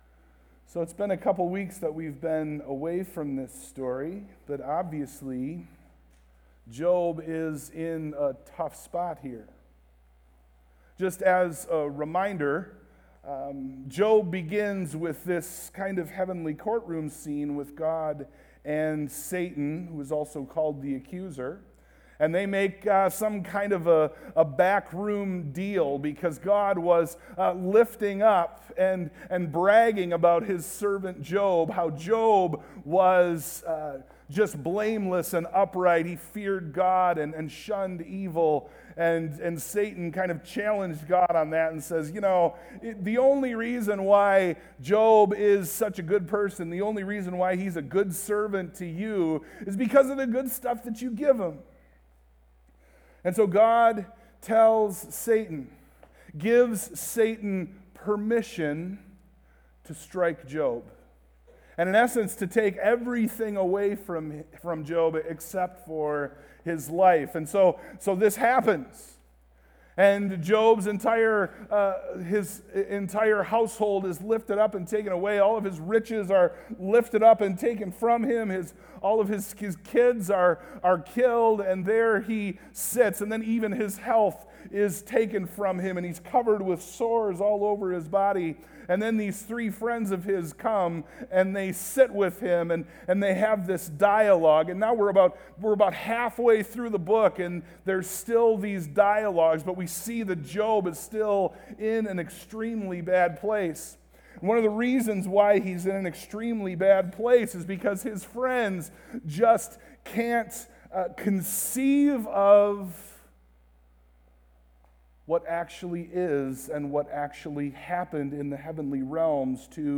Sermon+Audio+-+I,+and+not+Another.mp3